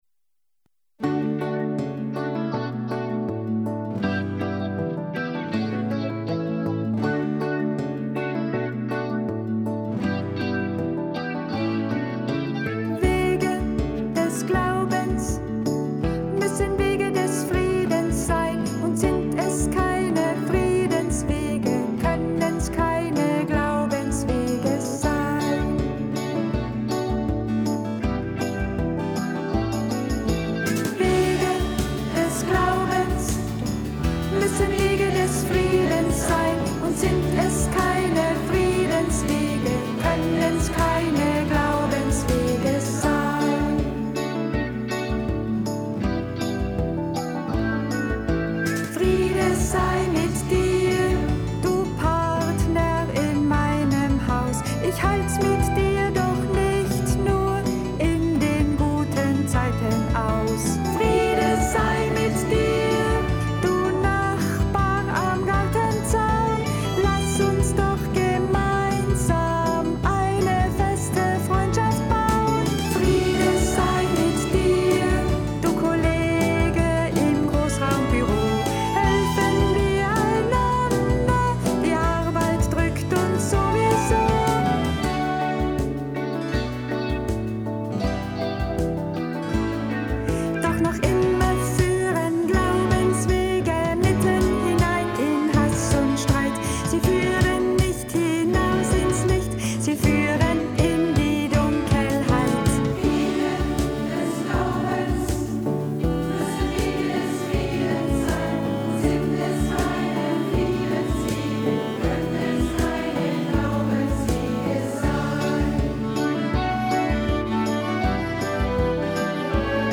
Uraufführung in Alt - St. Margaret
Kleine Messe für Zweifler und Grübler
Aufgeführt wurde diese Messe am Samstag, 25. Oktober  und am Sonntag, 26.10. in der alten St. Margaret Kirche.